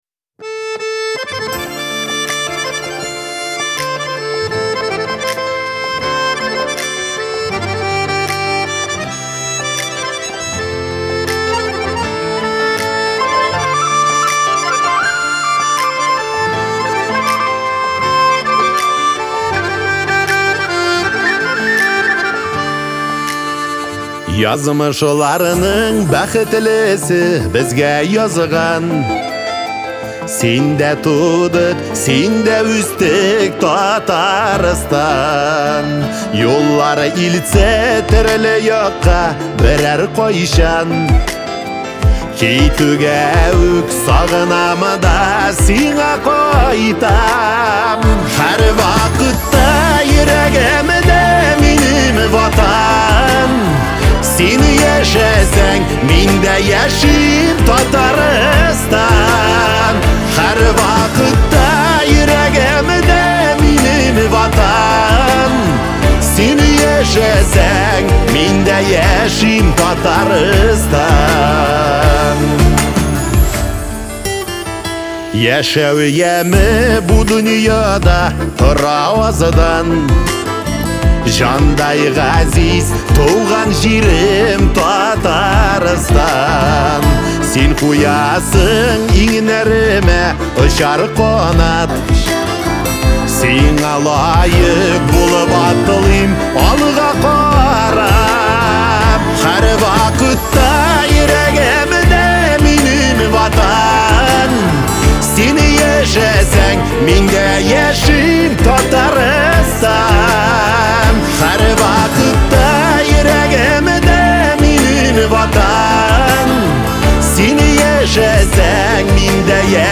Мелодия и текст создают атмосферу умиротворения и радости.